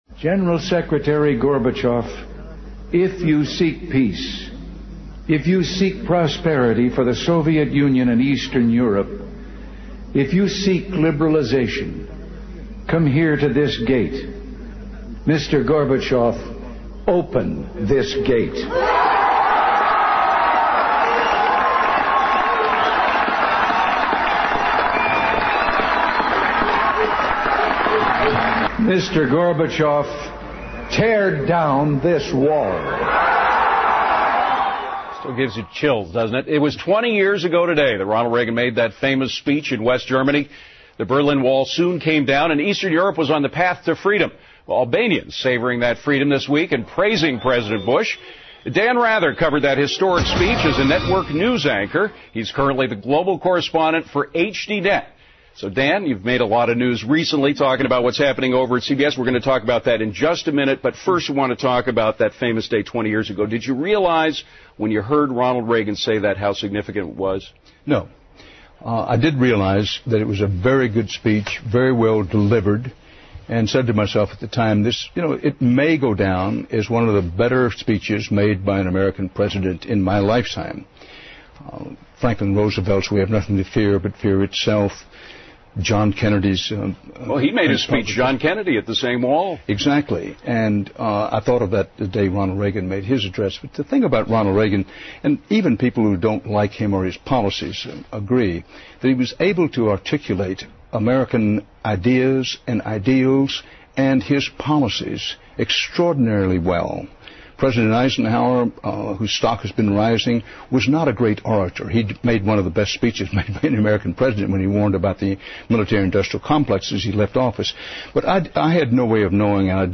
访谈录 Interview 2007-06-14&06-16, 永远的里根总统 听力文件下载—在线英语听力室